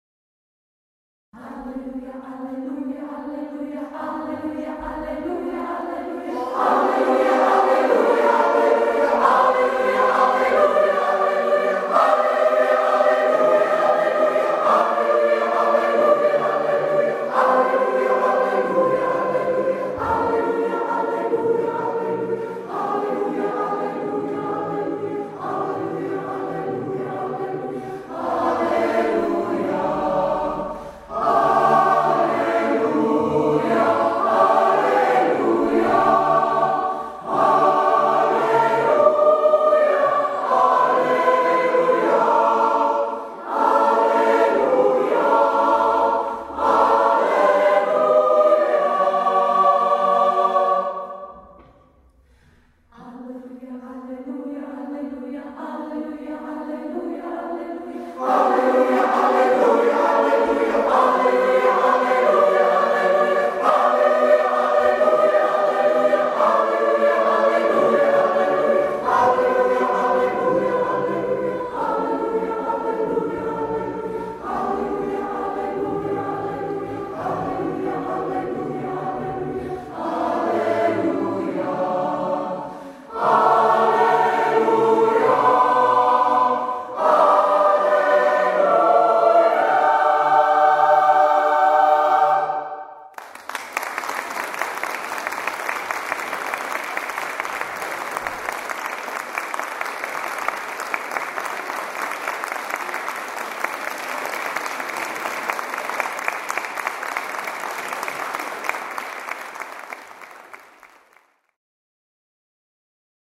Zvuková ukázka z vystoupení v kostele Panny Marie v Železné Rudě